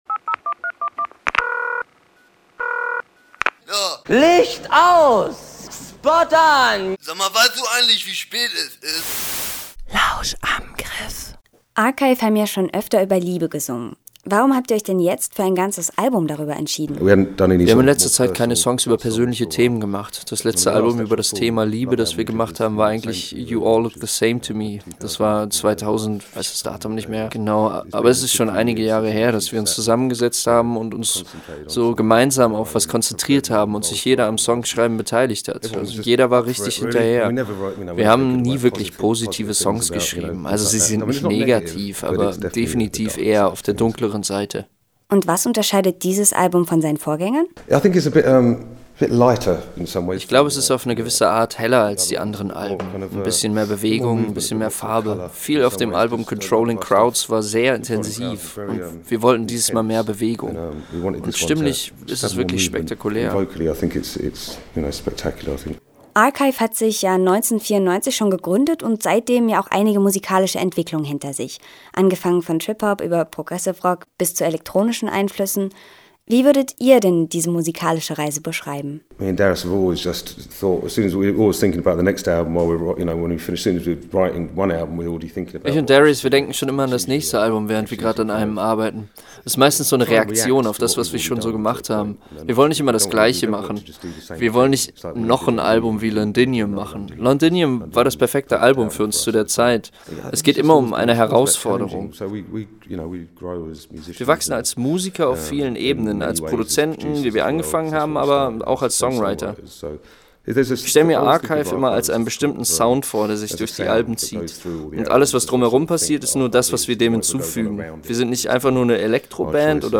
LAG: Archive im Interview – Campusradio Jena